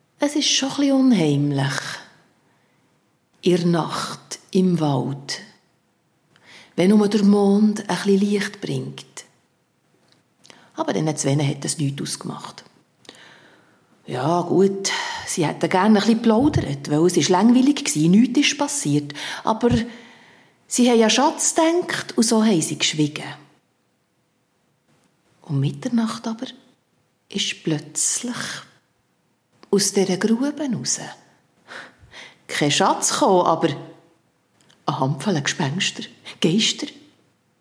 (Live-Aufnahmen im Museum Burghalde, Lenzburg)
Sprache Swiss German; Alemannic
Schlagworte Aargau • Geister • Kindermärchen • Lenzburg • Mond • Mundart • over the moon • Schatzsuche • Sonne